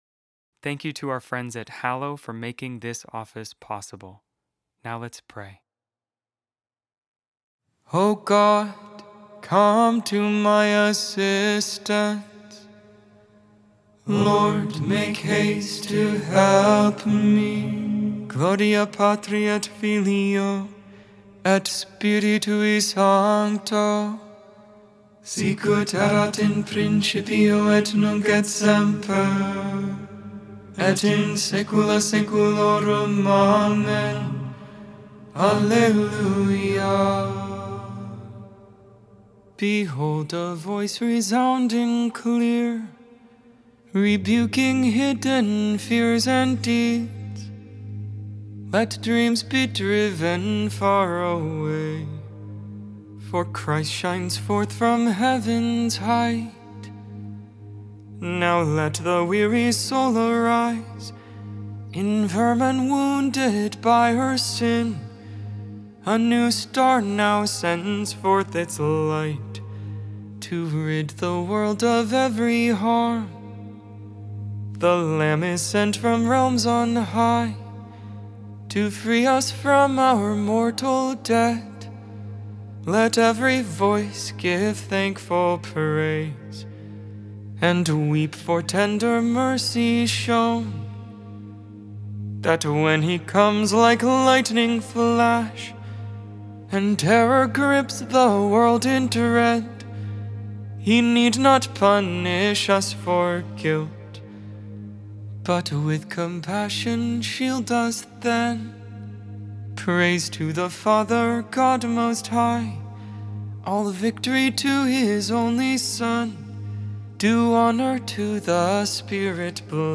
Lauds, Morning Prayer for the 1st Saturday in Advent, December 6, 2025.Optional Memorial for St. Nicholas, BishopMade without AI. 100% human vocals, 100% real prayer.